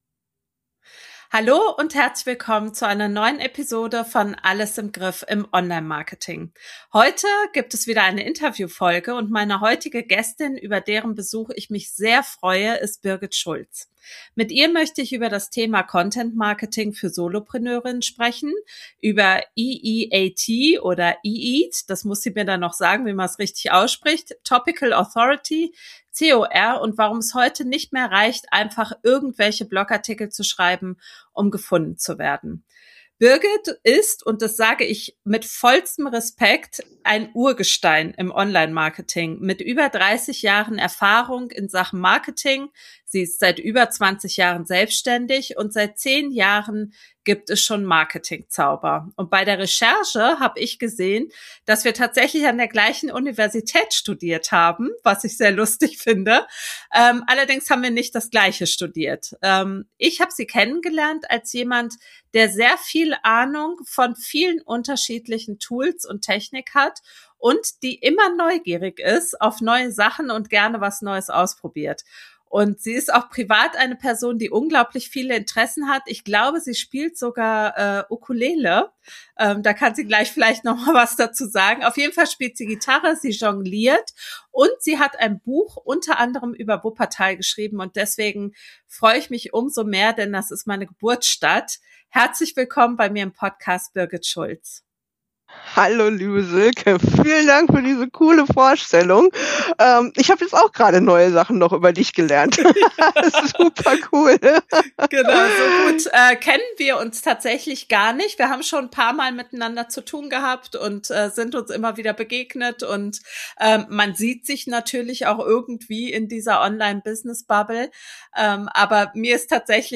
Wie dein Blog wieder für dich arbeitet - Interview